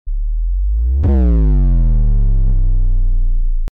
Rizz Bass Drop.mp3